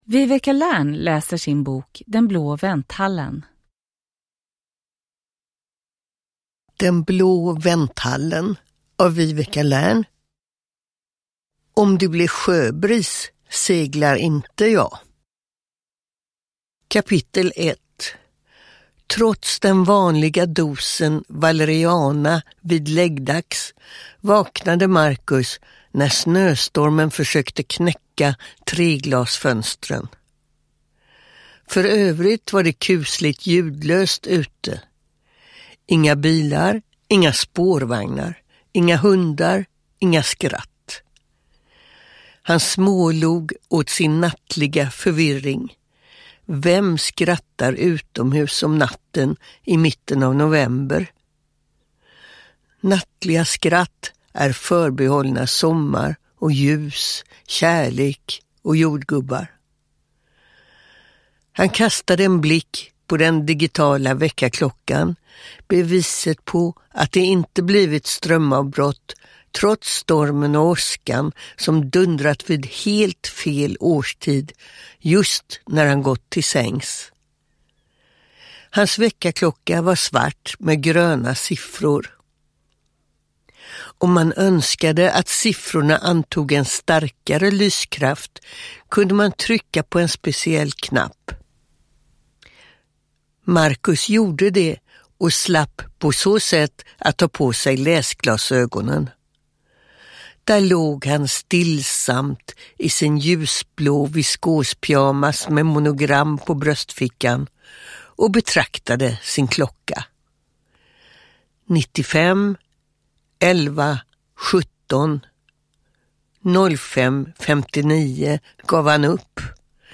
Uppläsare: Viveca Lärn
Ljudbok